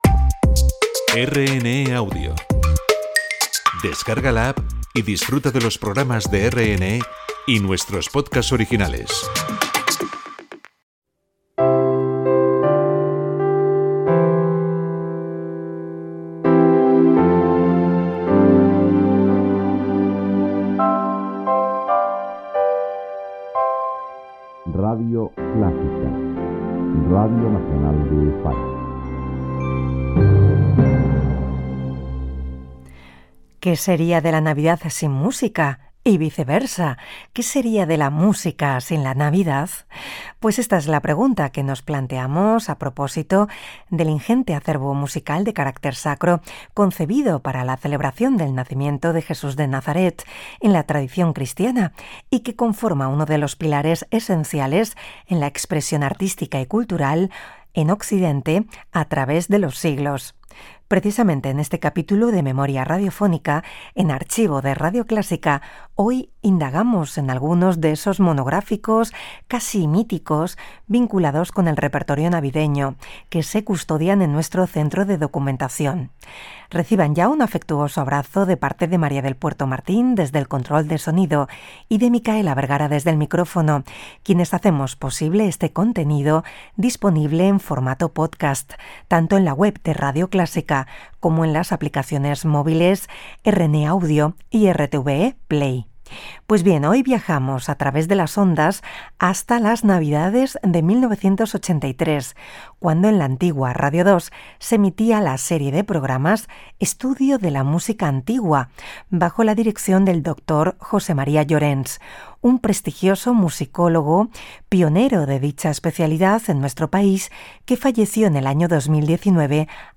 Identificació de RNE Audio i de la ràdio, presentació i espai dedicat a recordar dos espais de l'any 1983 de Radio 2 dedicats al Nadal
Musical